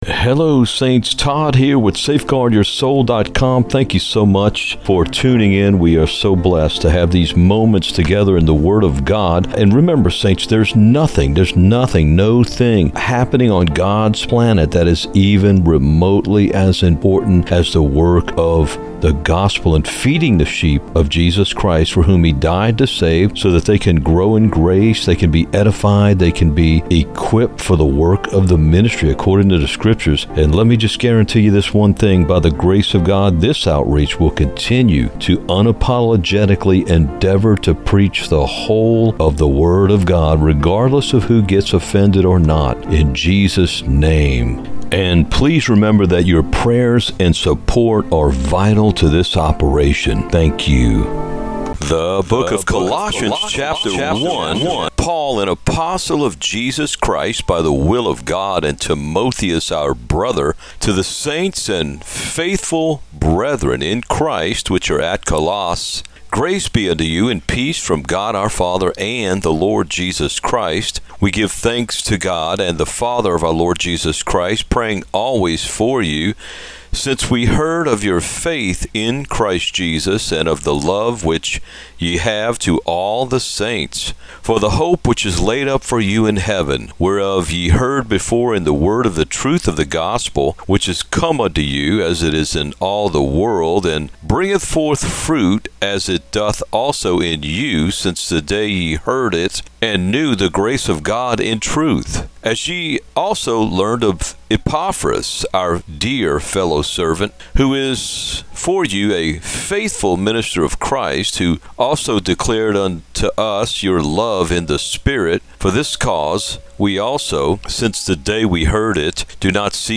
Colossians Narrated [podcast] - SafeGuardYourSoul
colossians-narrated2-EDITED-MUSIC.mp3